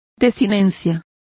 Complete with pronunciation of the translation of endings.